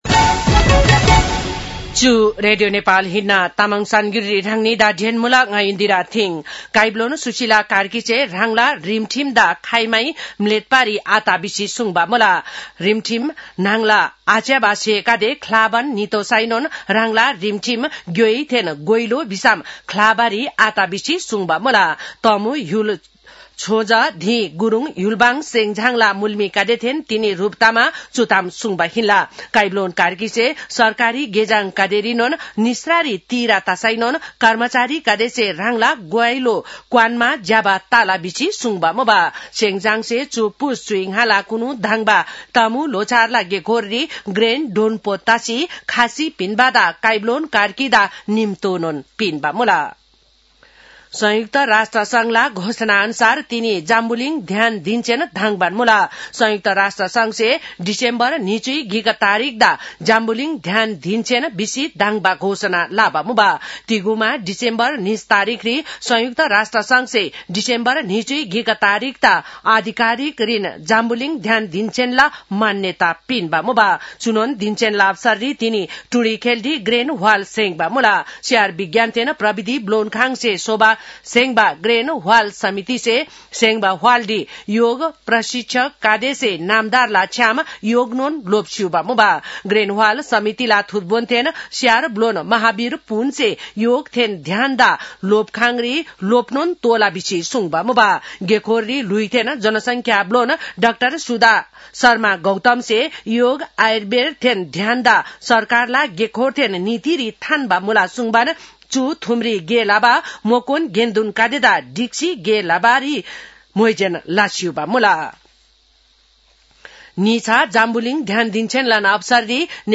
An online outlet of Nepal's national radio broadcaster
तामाङ भाषाको समाचार : ६ पुष , २०८२
Tamang-news-9-06.mp3